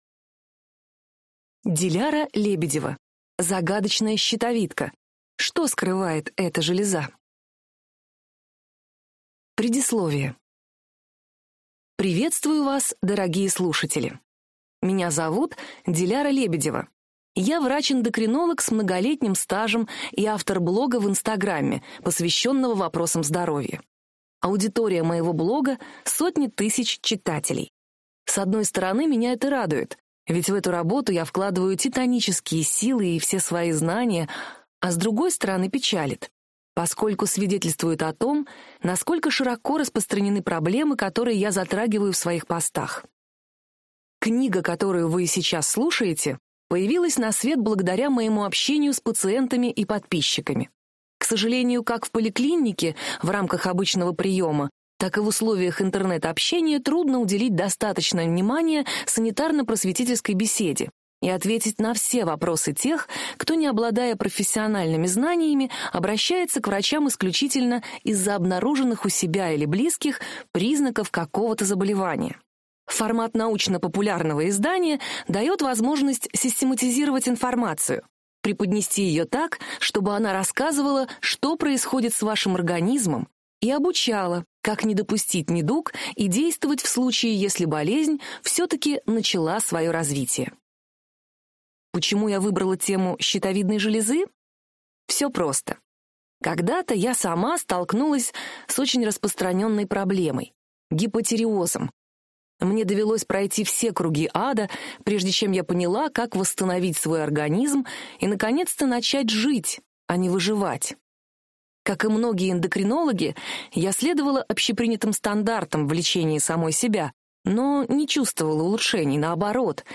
Аудиокнига Загадочная щитовидка: что скрывает эта железа | Библиотека аудиокниг